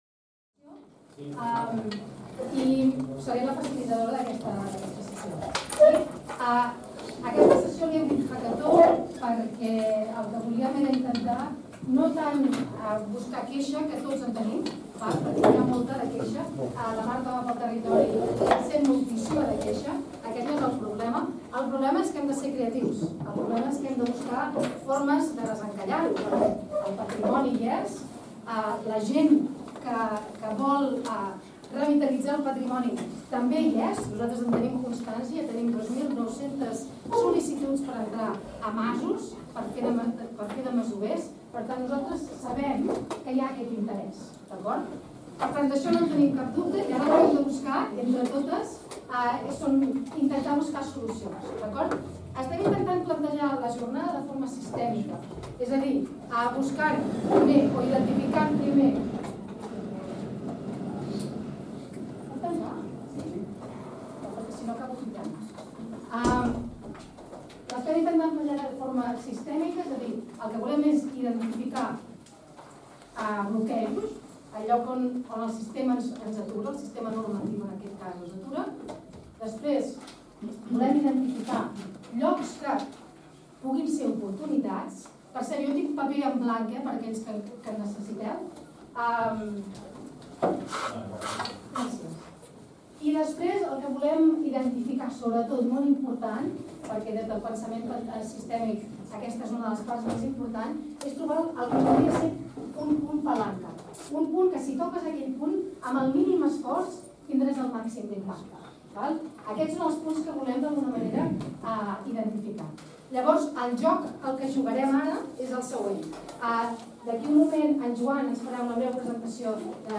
Jornada d'intercanvi sobre la Revitalització del Patrimoni Rural   2025-11-14  Text Complet  8-masos-vius.mp3 60.84 Mb | MP3     Compartir  </>  Primera Jornada d'Intercanvi sobre la Revitalització del Patrimoni Rural "Masos Vius" celebrada el 14 de novembre de 2025 i organitzada per la Fundació Mas i Terra i el Campus de Patrimoni Cultural i Natural de la Universitat de Girona, amb la col·laboració de l'Observatori de la Rehabilitació i Renovació Urbana de les Comarques de Girona, el Departament de Geografia i la Càtedra de Geografia i Pensament Territorial de la Universitat de Girona, la Fundació Girona Regió del Coneixement i la Demarcació de Girona del COAC. Amb aquestes jornades es vol crear un espai de confluència entre els diversos actors implicats en el món de la masia catalana per identificar i desenvolupar respostes als reptes actuals.